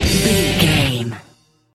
Aeolian/Minor
drums
electric guitar
bass guitar
Sports Rock
hard rock
aggressive
energetic
intense
nu metal
alternative metal